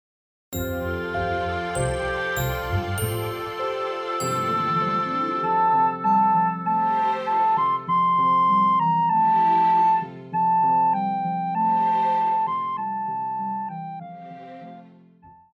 古典
高音直笛
樂團
聖誕歌曲,傳統歌曲／民謠,聖歌,教會音樂,古典音樂
獨奏與伴奏
有主奏
有節拍器